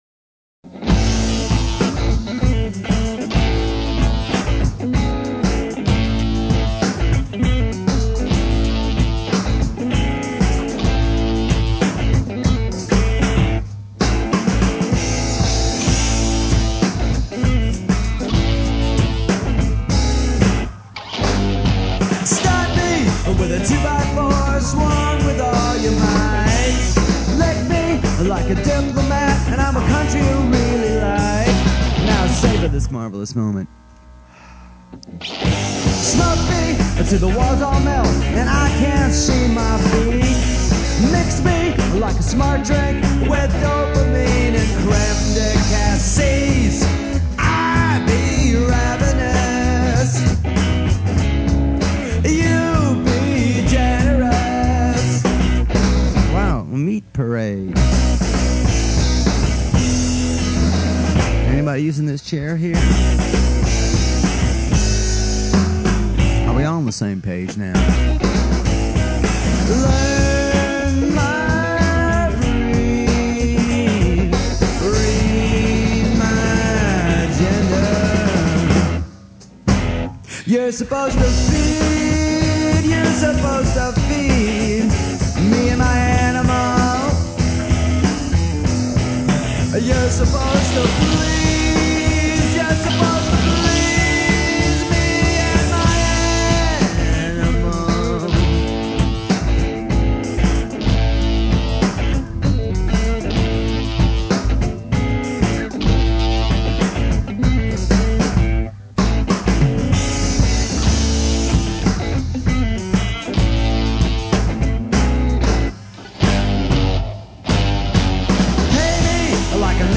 A rare chance to hear the creative process at work:
a 4-track demo